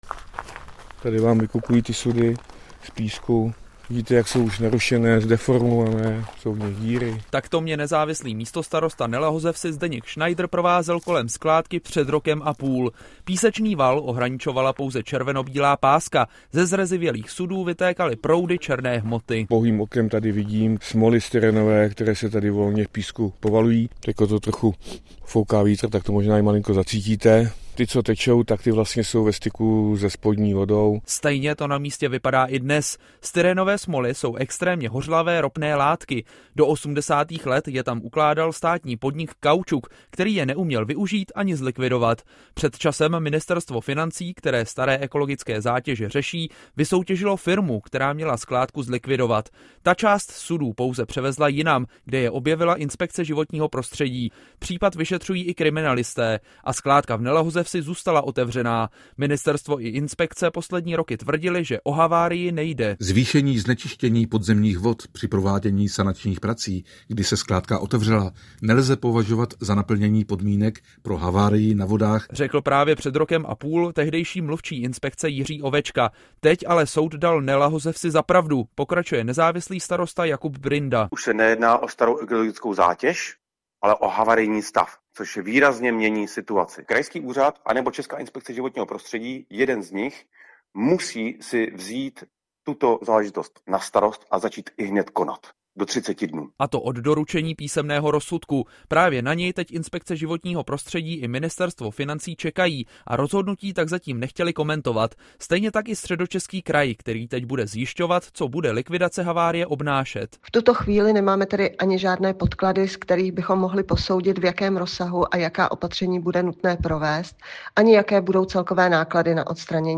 Zprávy Českého rozhlasu Střední Čechy: Nebezpečná skládka chemikálií v Nelahozevsi se musí okamžitě zlikvidovat, rozhodl soud - 06.02.2025